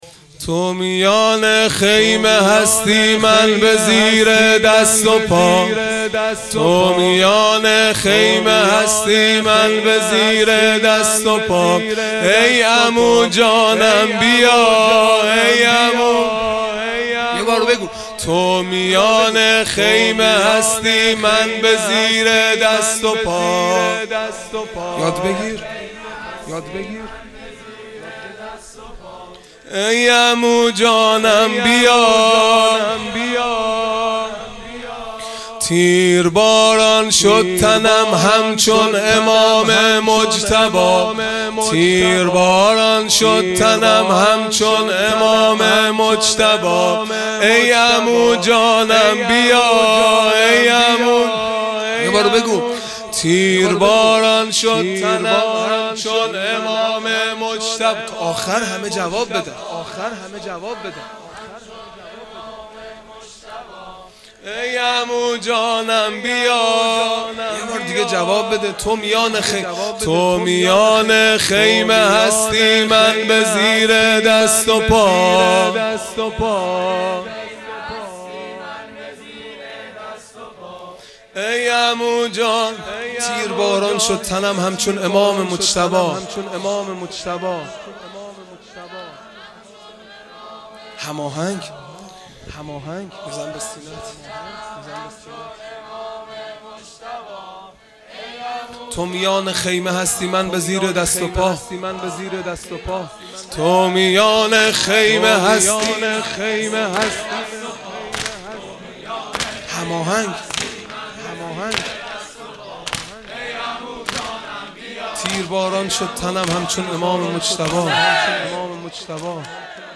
دودمه | تو میان خیمه هستی من به زیر دست و پا | شنبه ۲۳ مرداد ۱۴۰۰
دهه اول محرم الحرام ۱۴۴۳ | شب ششم | شنبه ۲۳ مرداد ۱۴۰۰